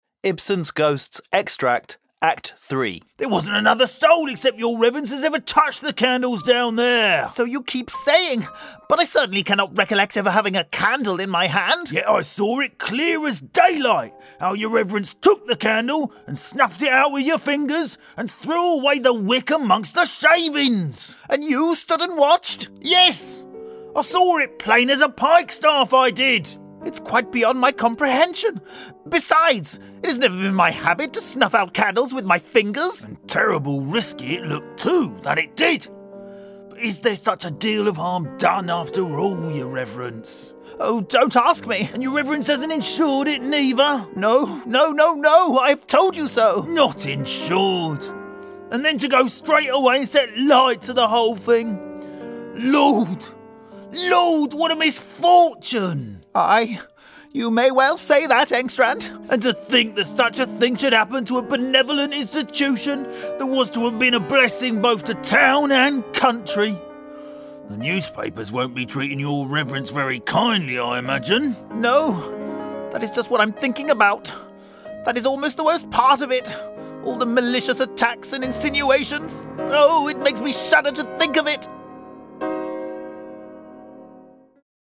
Male
English (British)
I have a versatile and expressive mid to deep British voice.
I can also do a variety of accents and can express a wide range of emotion and am comfortable producing comedic, atmospheric and unearthly voices.
Audiobooks
Words that describe my voice are Professional, Sincere, Expressive.
0428Ghosts_ActingReel_Act3_snippet.mp3